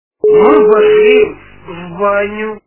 » Звуки » Люди фразы » Голос - Мы пошли в баню
Звук Голос - Мы пошли в баню